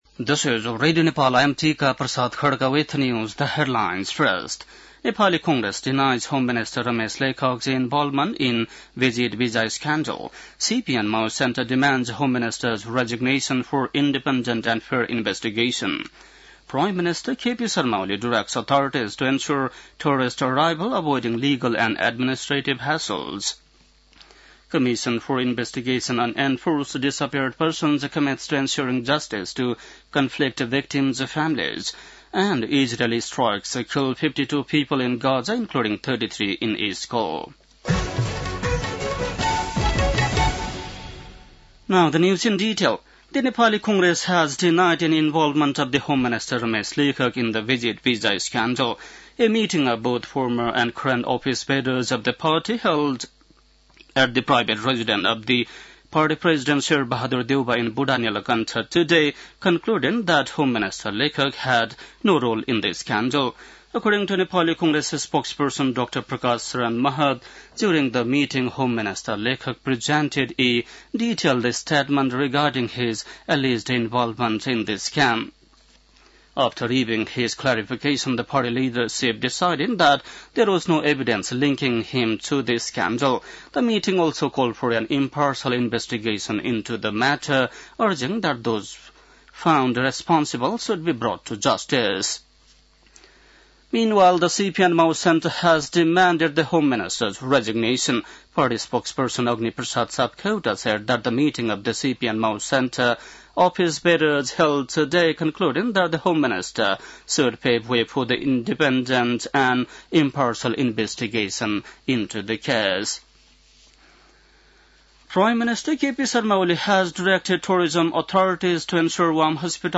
बेलुकी ८ बजेको अङ्ग्रेजी समाचार : १२ जेठ , २०८२
8-pm-english-news-1-1.mp3